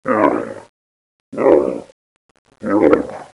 دانلود صدای شتر 3 از ساعد نیوز با لینک مستقیم و کیفیت بالا
جلوه های صوتی